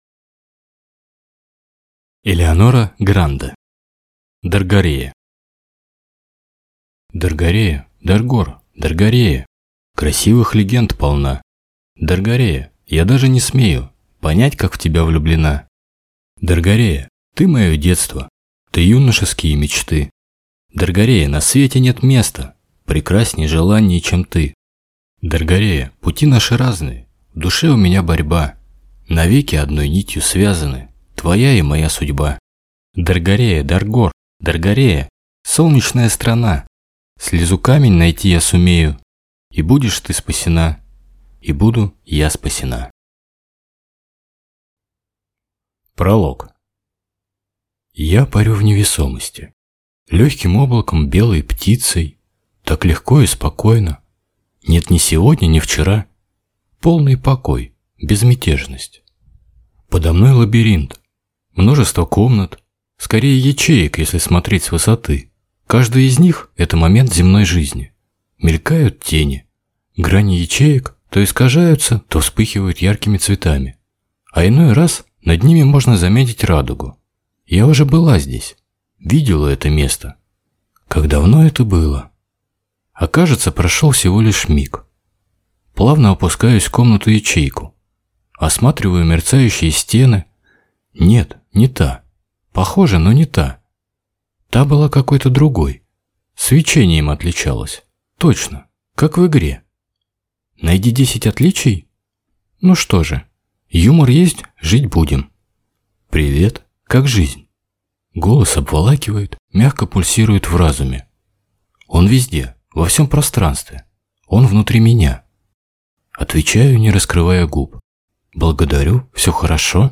Аудиокнига Даргорея | Библиотека аудиокниг
Прослушать и бесплатно скачать фрагмент аудиокниги